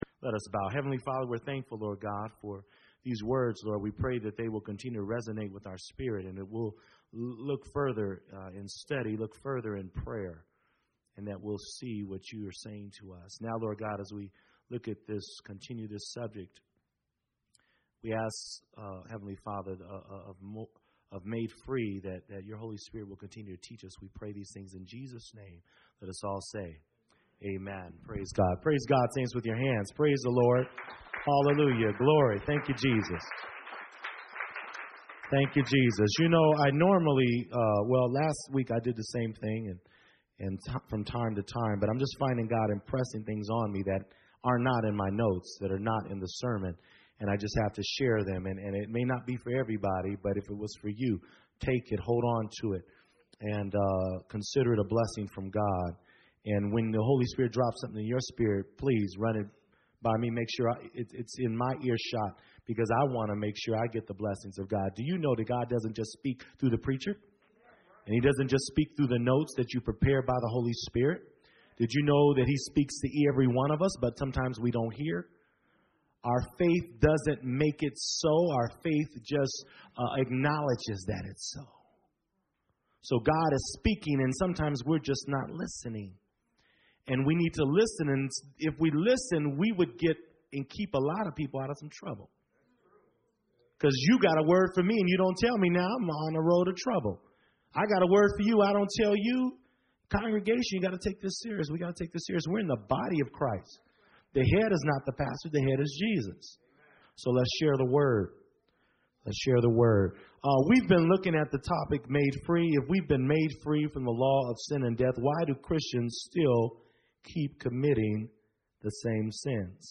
Imani Sermons